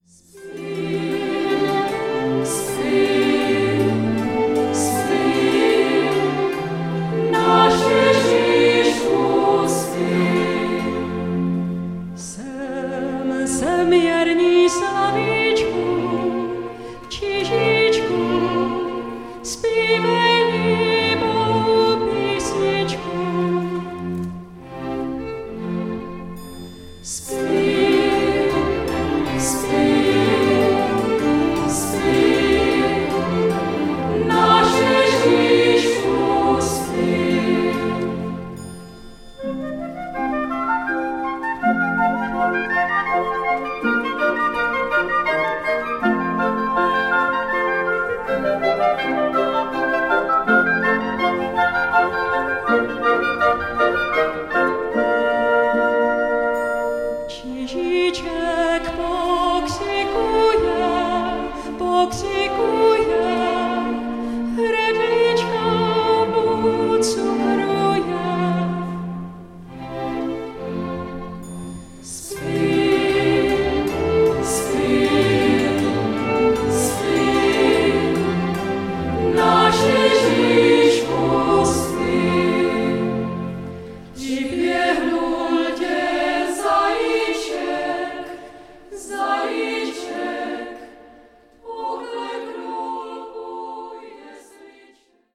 lidová
zpěv